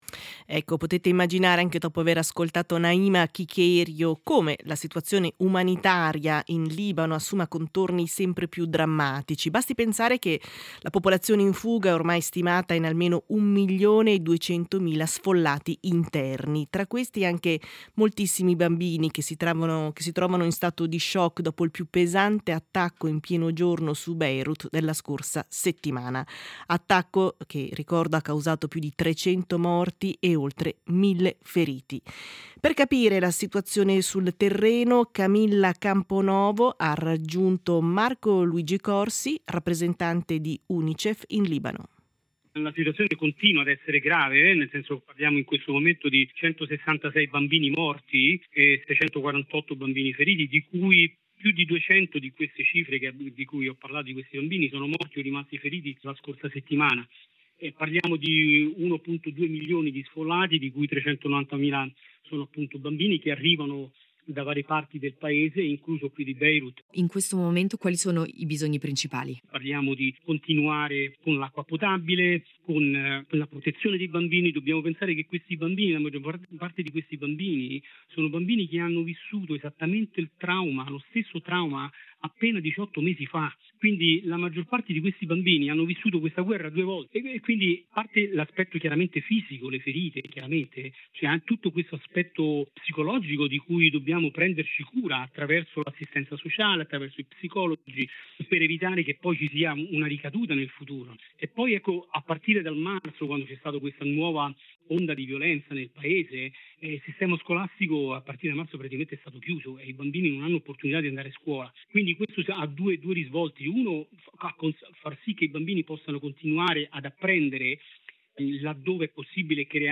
SEIDISERA del 14.04.2026: Intervista a Unicef Libano